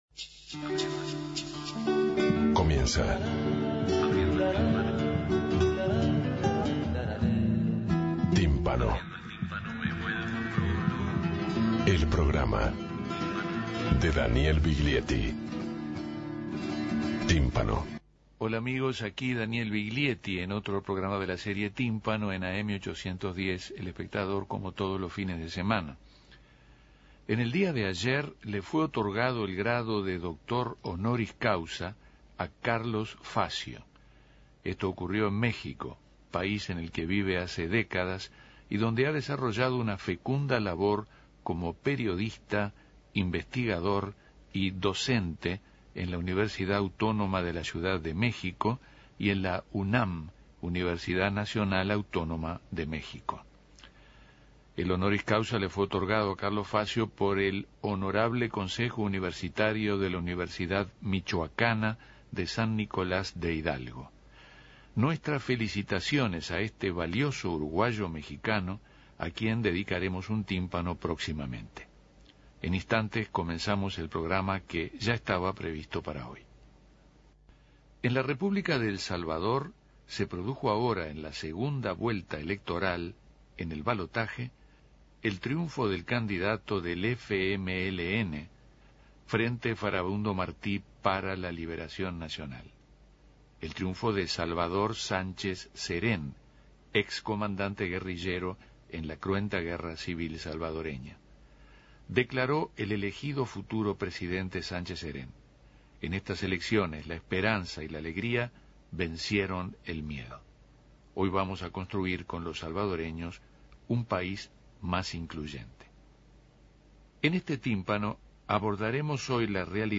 En este programa de Tímpano se escuchará la memoria histórica del creador salvadoreño, sus poemas y su voz.